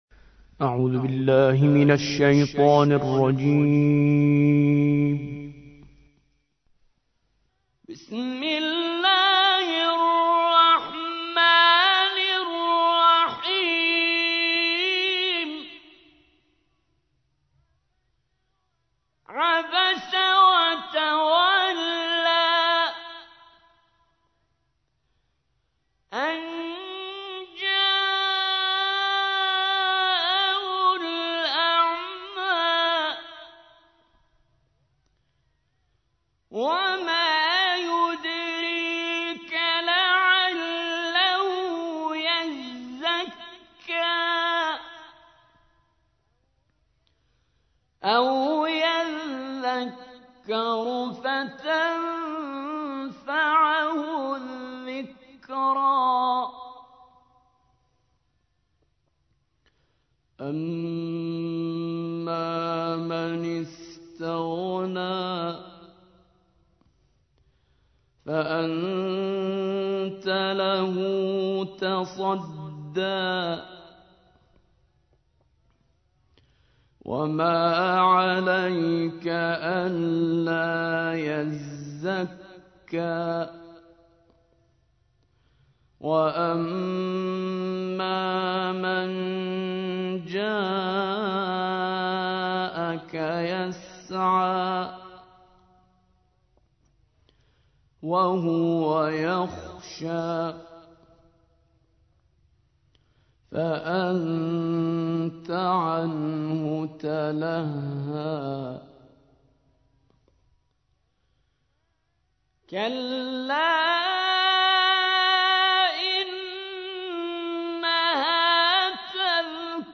80. سورة عبس / القارئ